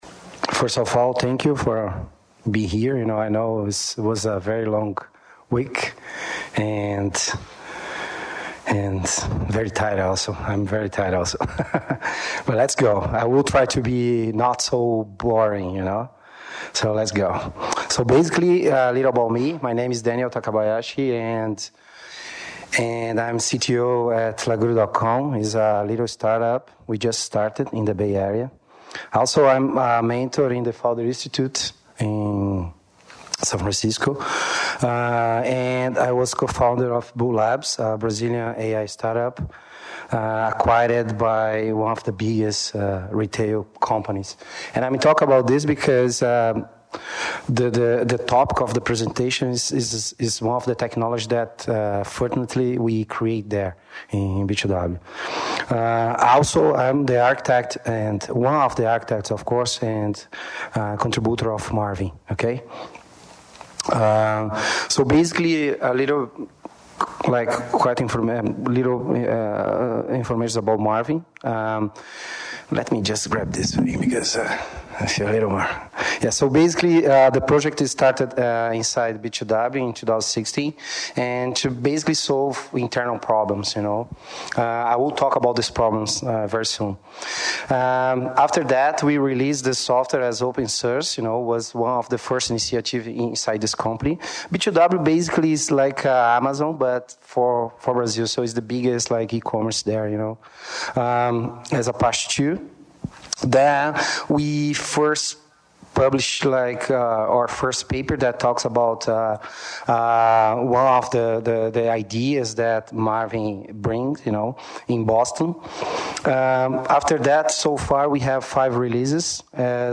Marvin aims at abstracting the complexities in the creation process of scalable, highly available, interoperable and maintainable predictive software. In this presentation, the speaker is going to talk about the architecture behind the platform and show the cooler features of Apache Marvin.